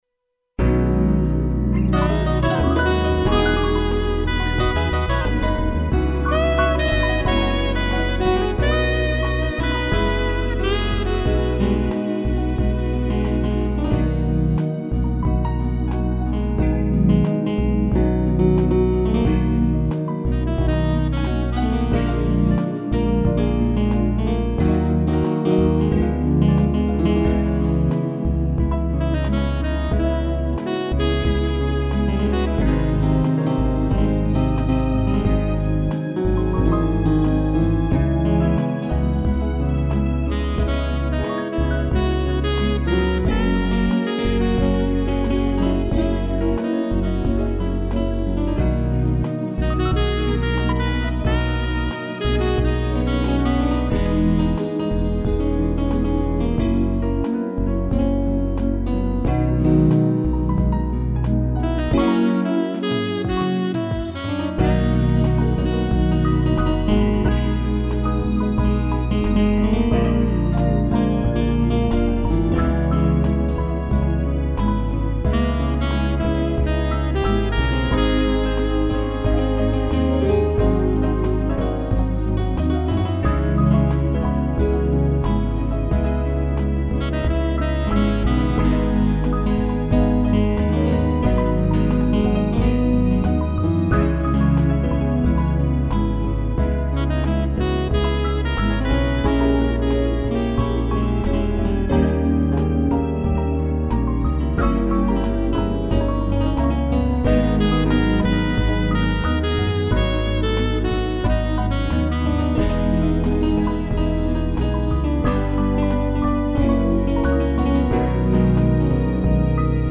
piano, sax e strings
instrumental